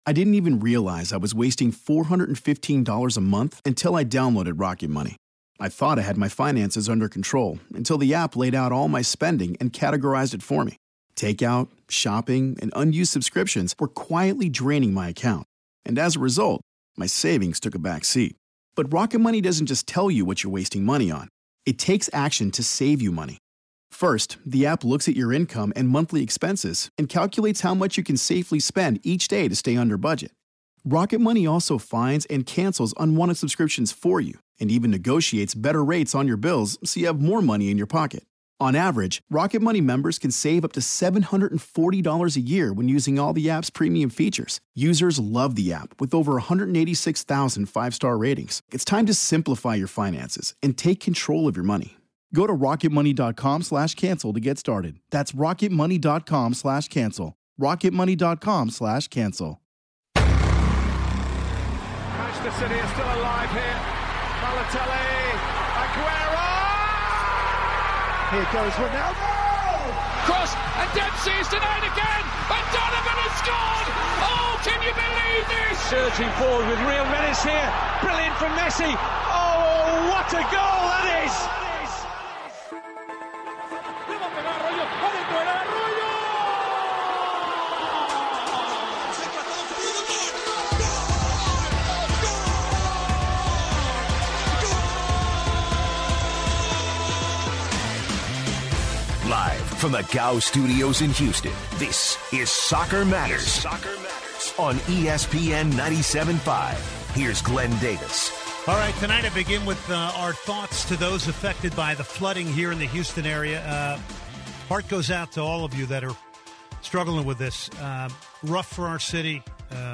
taking phone calls plus discussion on managerial moves and possible replacements. Carlo Ancelotti is out at Real Madrid, who will take his place? Will Brendan Rodgers stay or go at Liverpool?